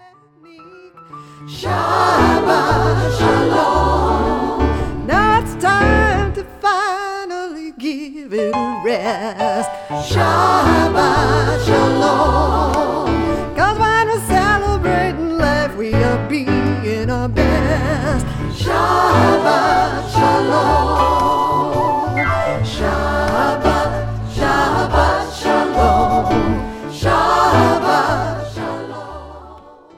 Through meditative chant and spiritual melody and text